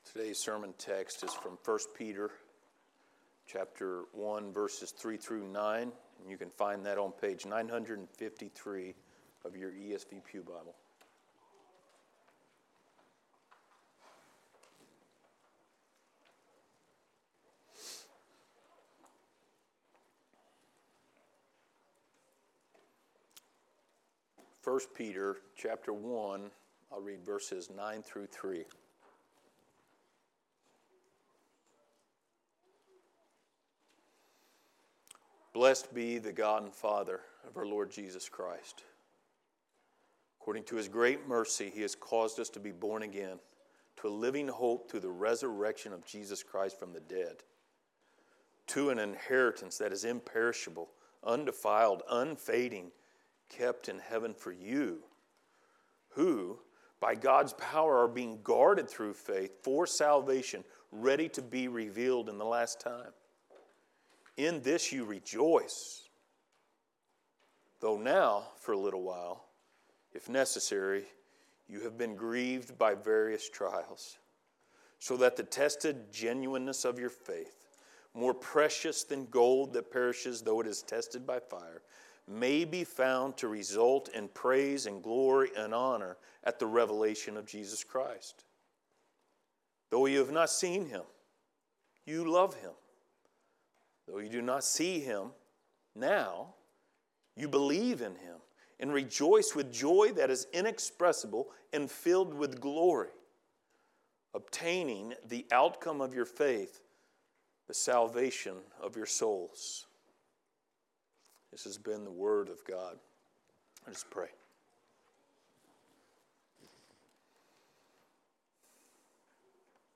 1 Peter Passage: 1 Peter 1:6-9 Service Type: Sunday Morning Related Topics